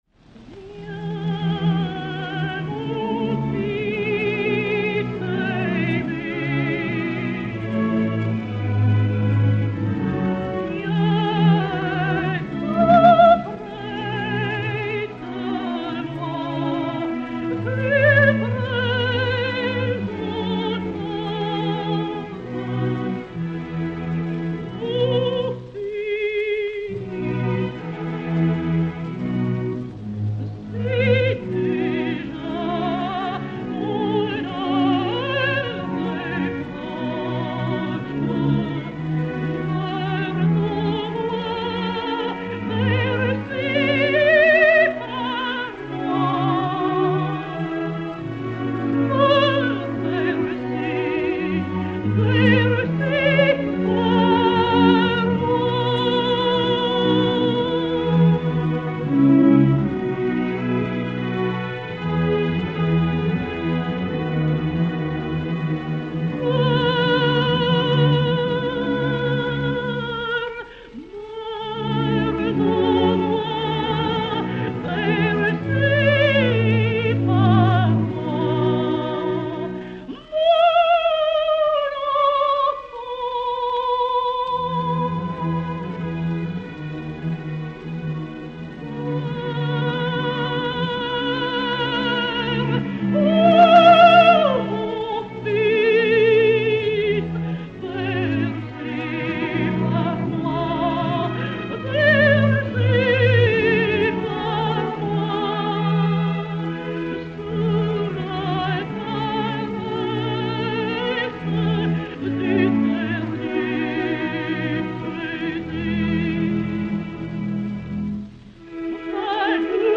Germaine Martinelli et Orchestre dir. Albert Wolff